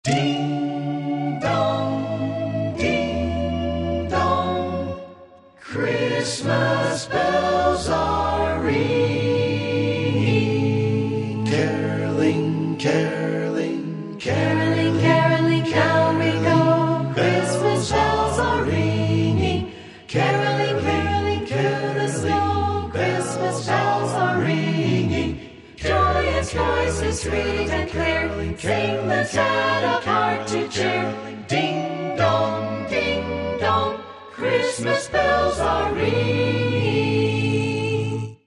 carols in their original form, a cappella.